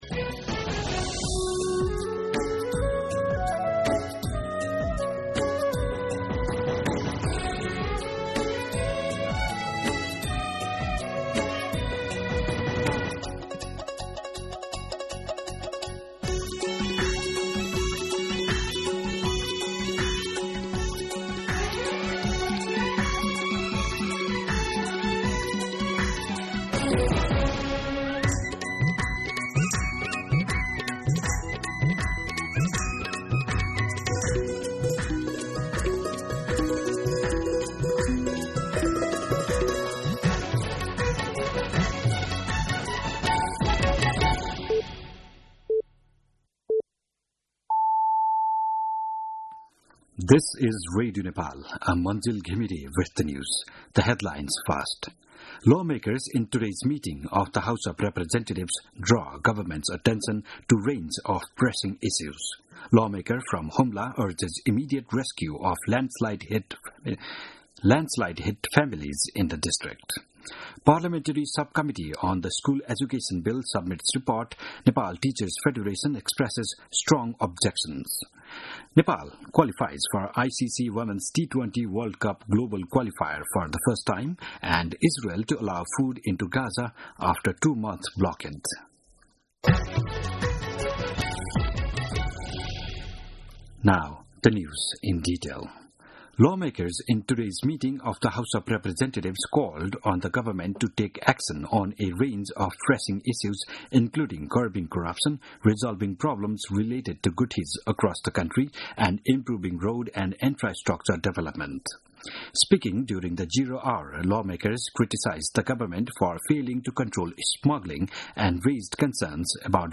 दिउँसो २ बजेको अङ्ग्रेजी समाचार : ५ जेठ , २०८२